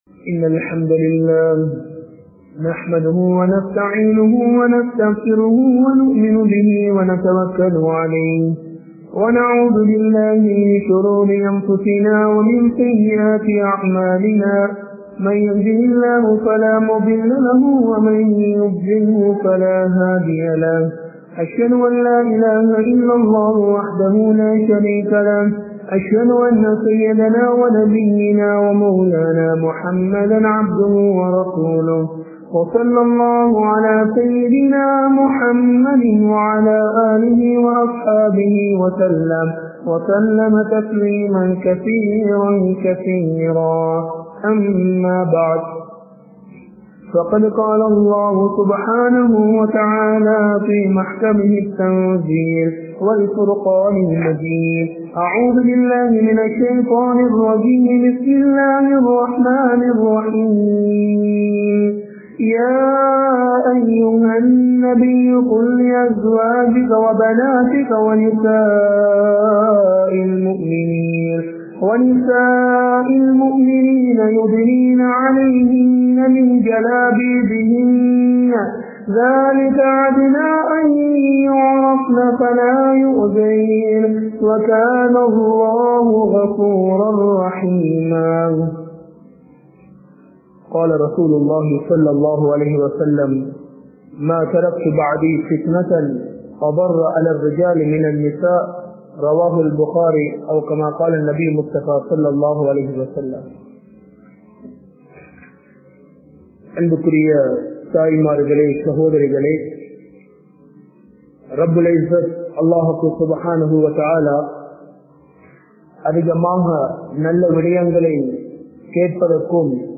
Islamiya Pengalin Aadai Murai (இஸ்லாமிய பெண்களின் ஆடை முறை) | Audio Bayans | All Ceylon Muslim Youth Community | Addalaichenai